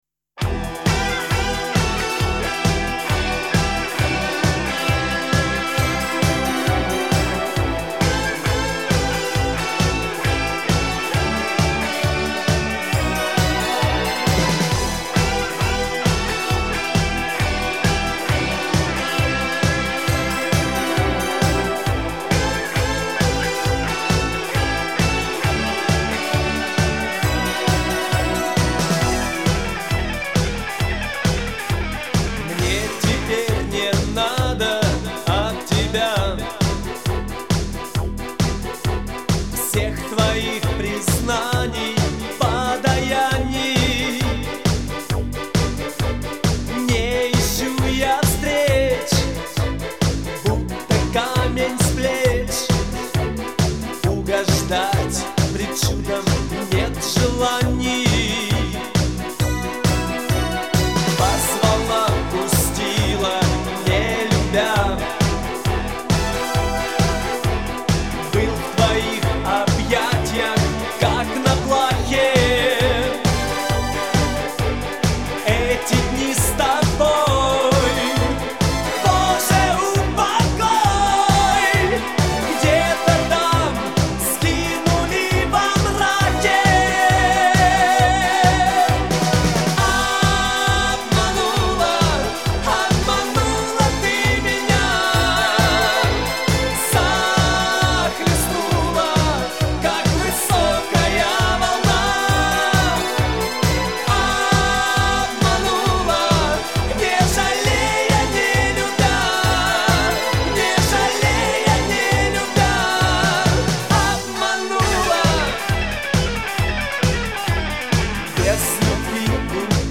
Всі мінусовки жанру Disco
Плюсовий запис